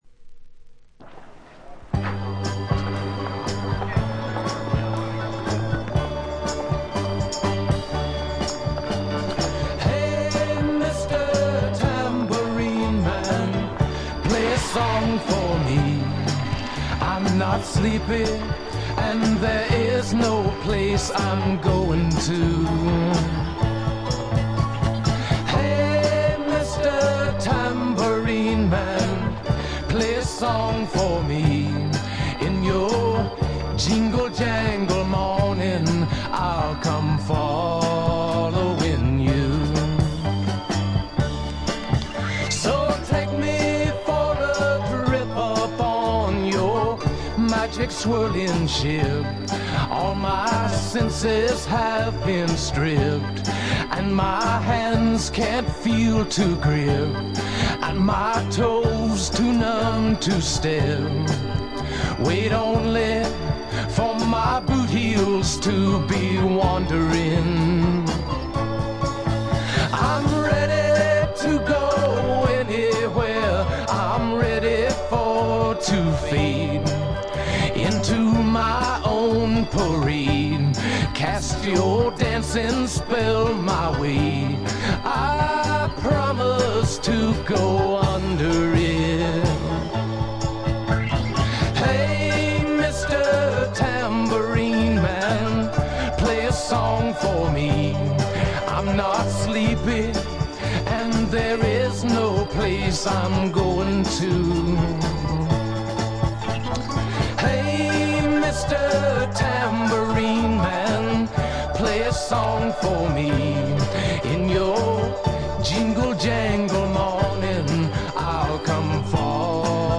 試聴曲は現品からの取り込み音源です。